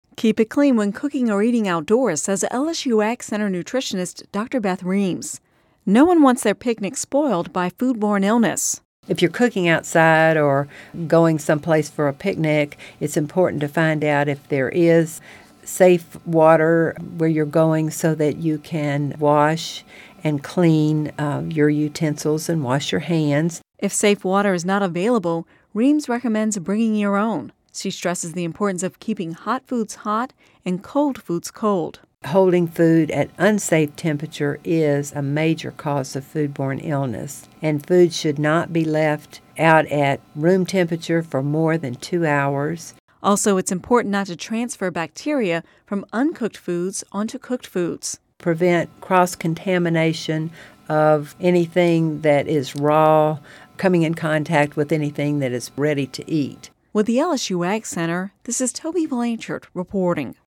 Radio News 6/28/10